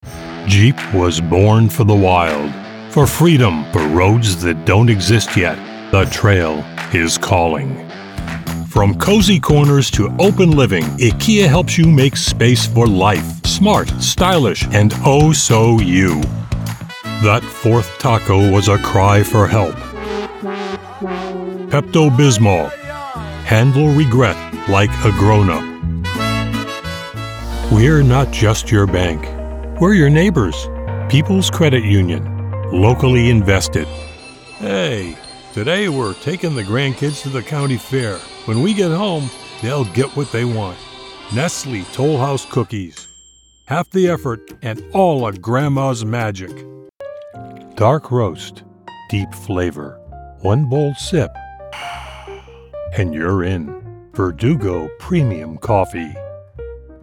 Male
English (North American)
Adult (30-50), Older Sound (50+)
I have pleasant baritone that holds your attention. It can be modulated for variety of intonations, from soothing to commanding.
Television Spots
Variety Of Ad Spots